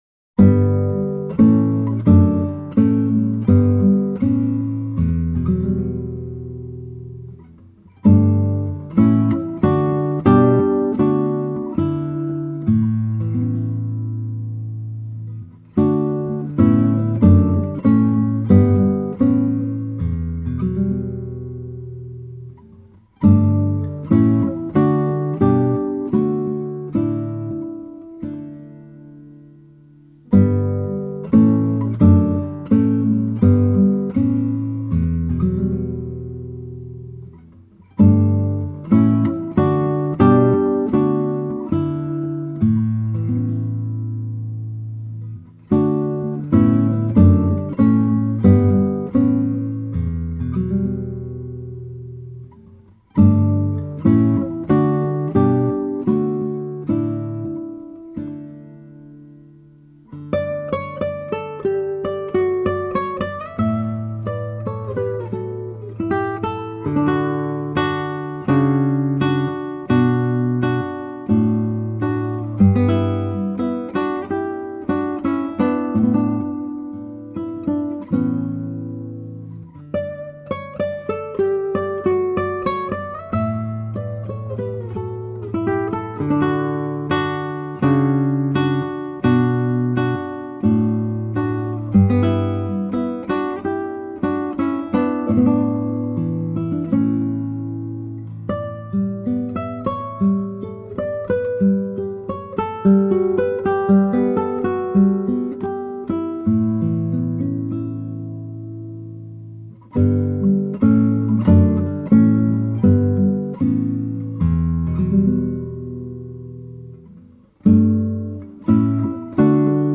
Outstanding classical guitar.